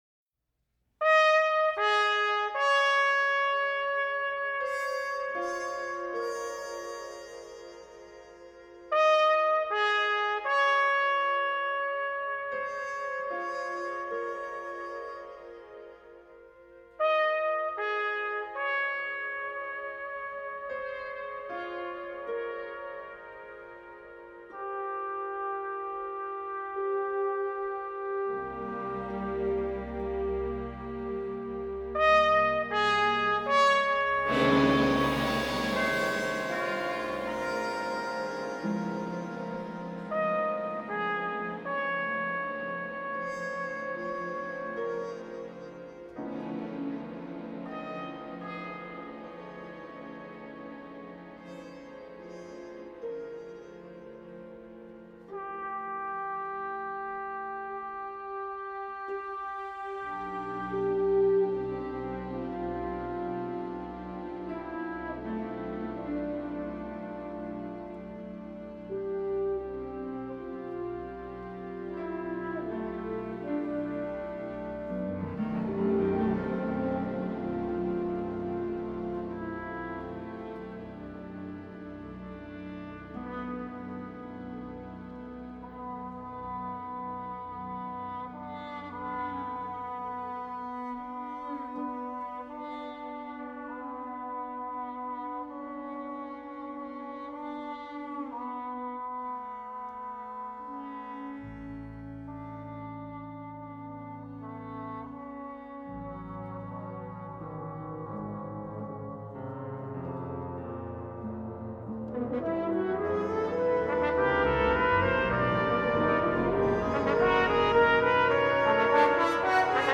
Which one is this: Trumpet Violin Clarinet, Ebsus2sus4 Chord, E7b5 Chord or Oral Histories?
Trumpet Violin Clarinet